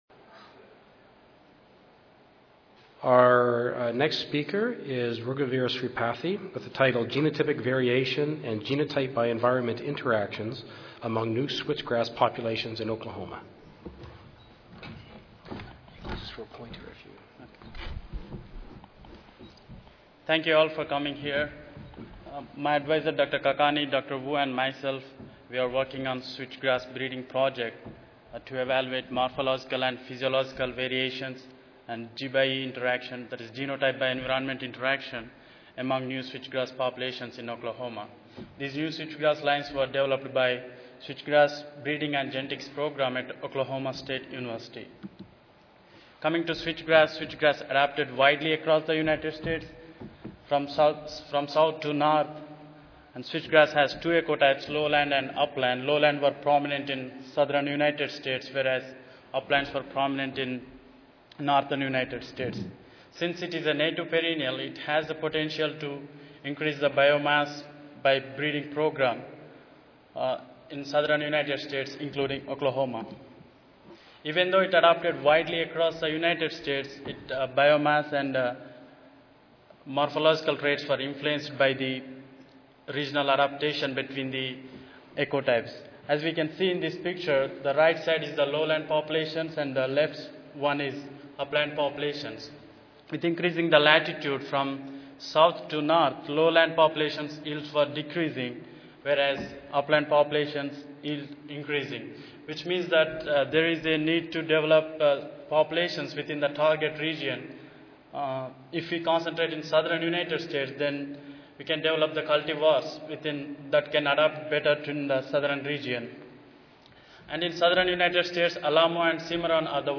Oklahoma State University Recorded Presentation Audio File